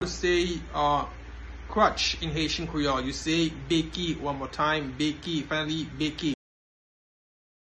Pronunciation:
Crutch-in-Haitian-Creole-Beki-pronunciation.mp3